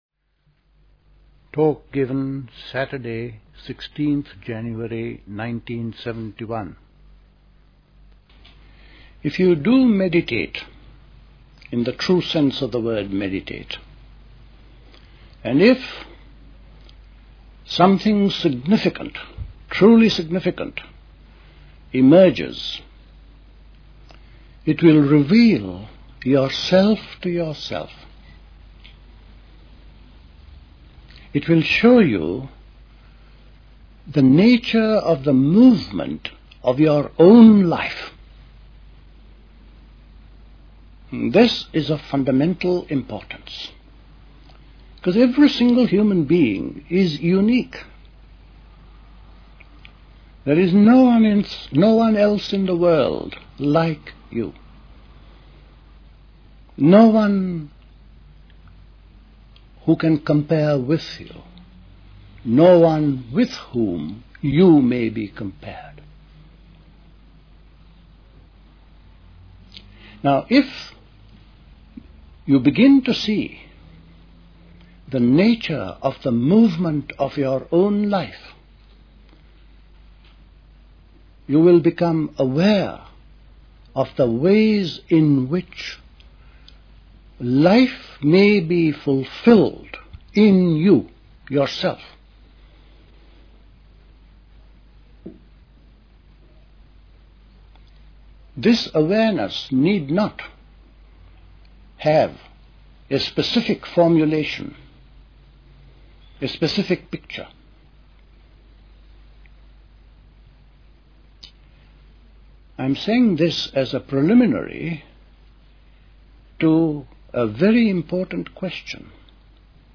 A talk
at Dilkusha, Forest Hill, London on 16th January 1971